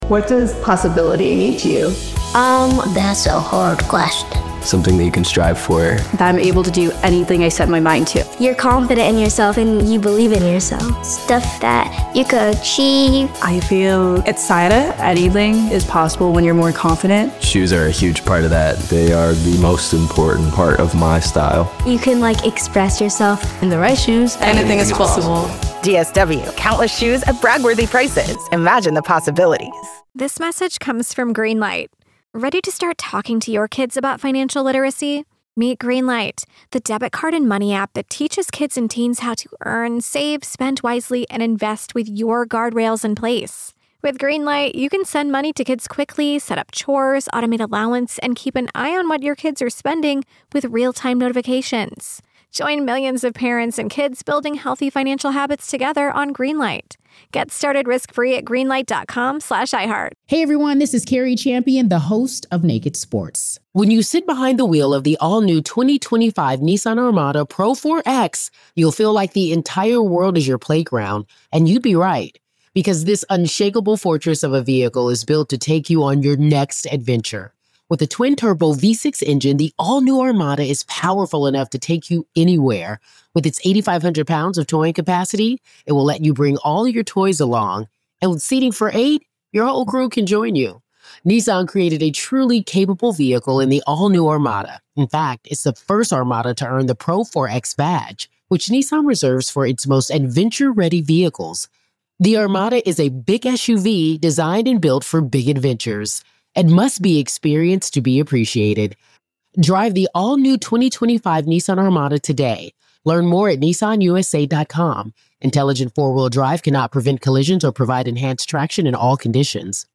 Retired FBI Special Agent